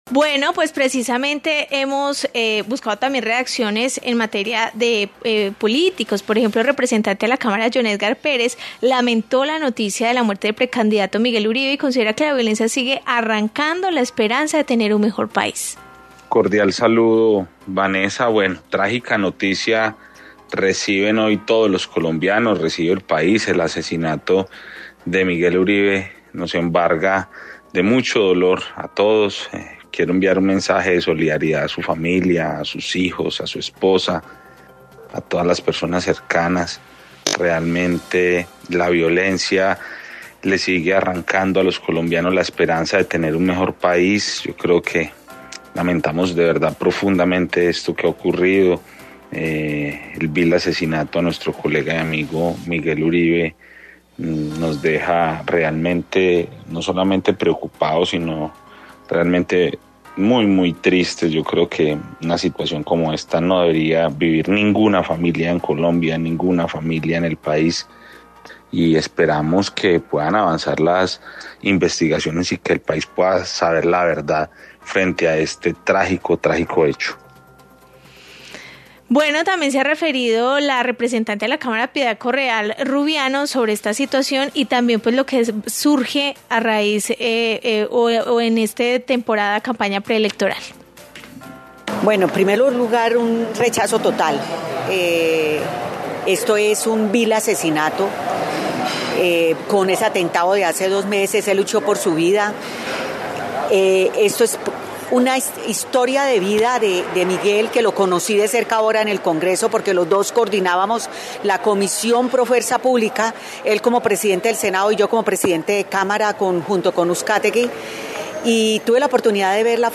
Informe sobre reacciones a la muerte de Miguel Uribe